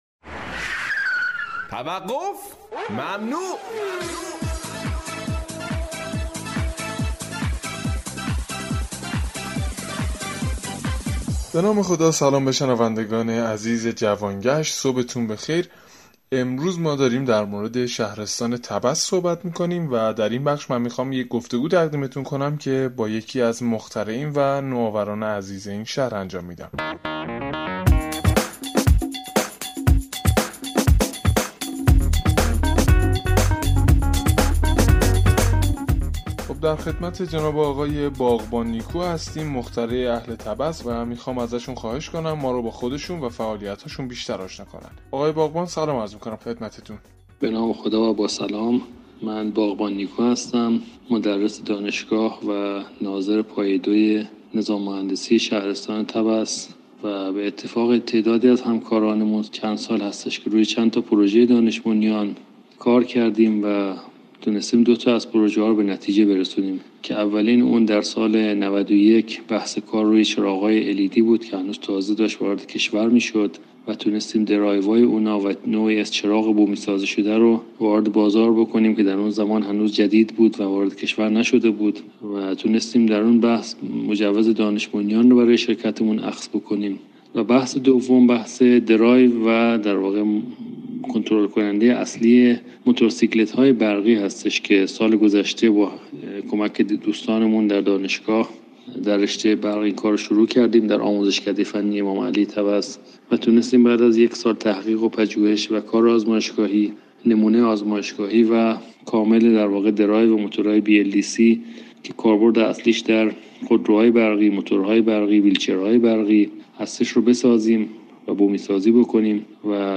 فایل صوتی این مصاحبه از طریق